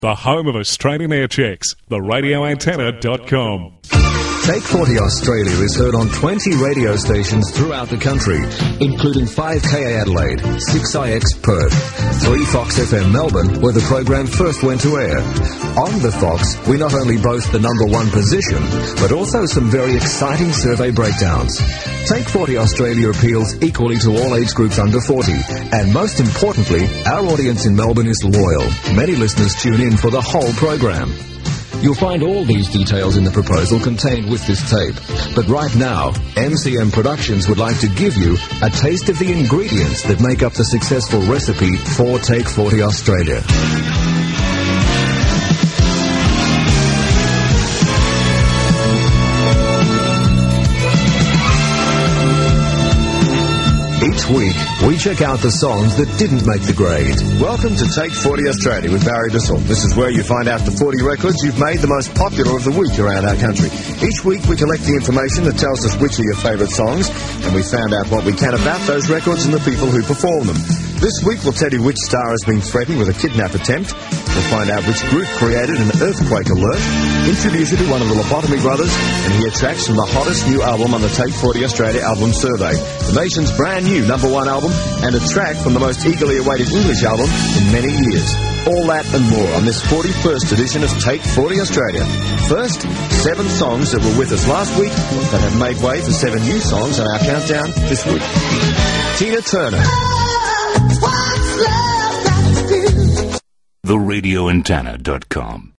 Smooth voiceover